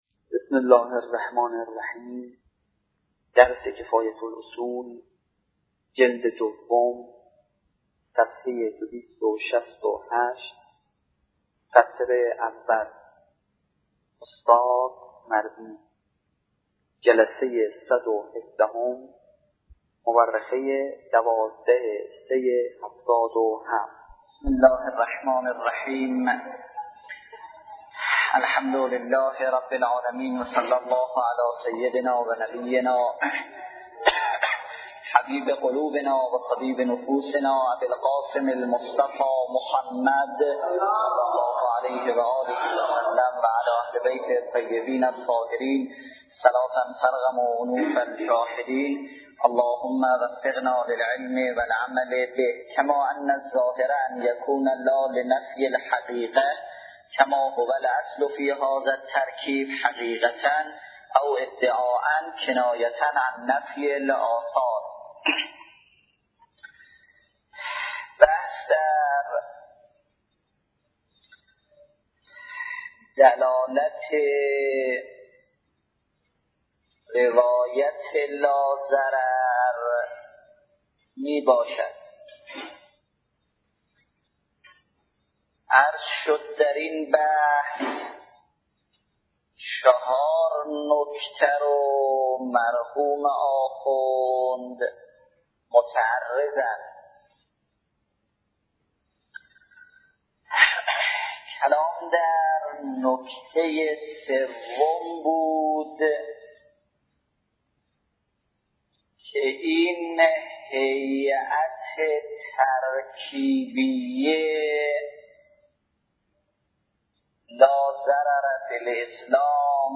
دسته : نیوایج